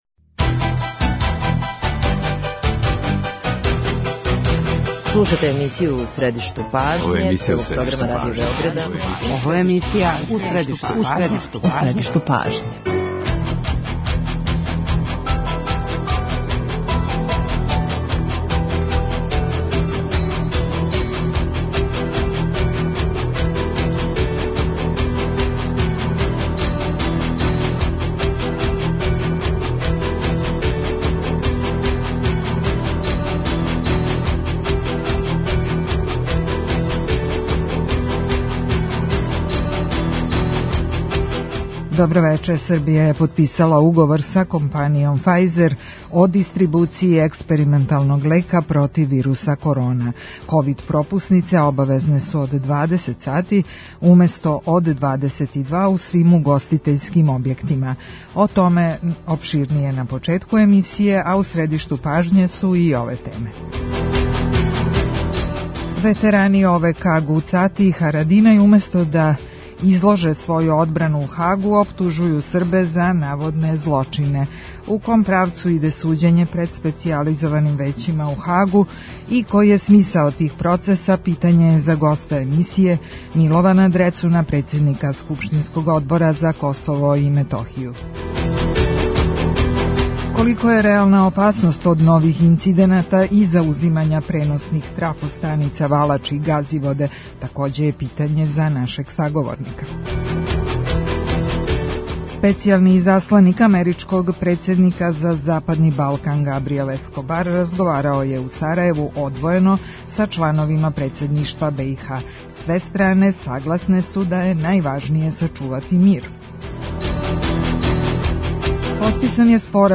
Гост емисије је Милован Дрецун, председник скупштинског Одбора за КиМ.